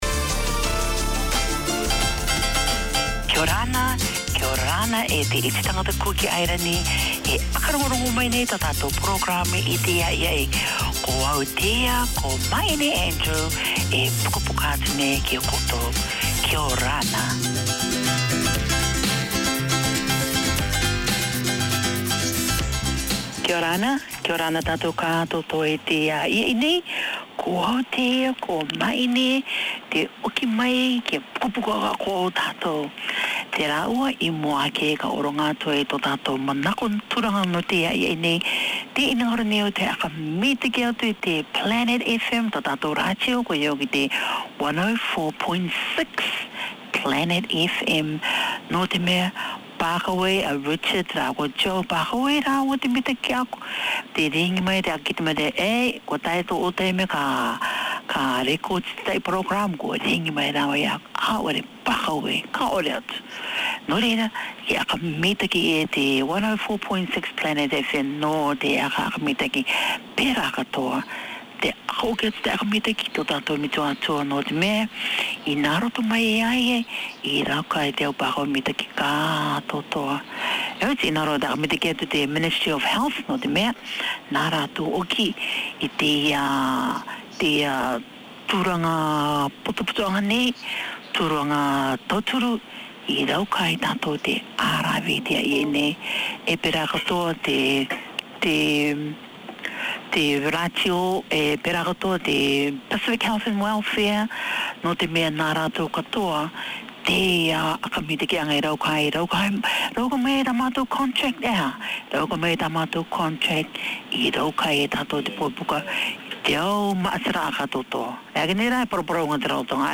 An outreach of the Pacific Islands Health and Welfare project under the auspices of the Auckland Health Board, Cook Islands Health is the half hour each week that keeps you in touch with health news, with interviews, information, community updates.